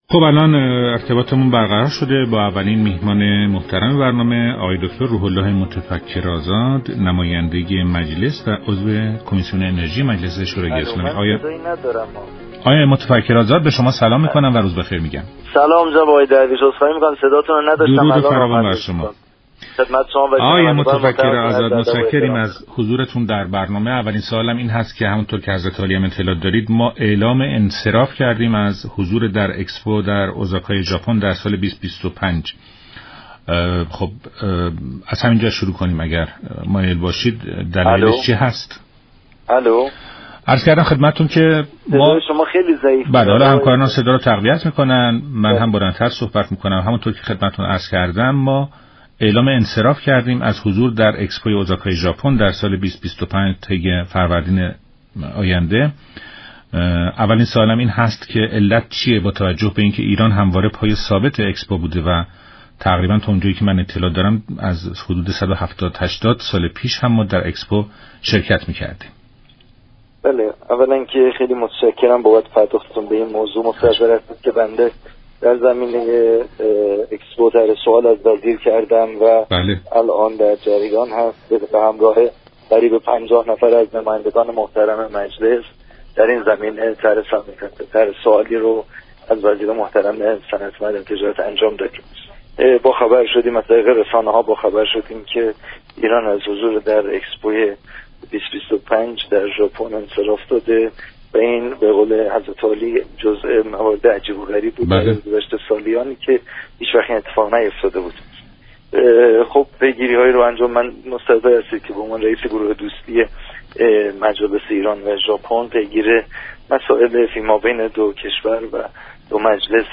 عضو كمیسیون انرژی مجلس در برنامه ایران امروز گفت: دولت معتقد است، مسئولان نمایشگاه اكسپو غرفه مناسب در شان و سلیقه ایرانی در اختیارمان قرار نداده‌اند.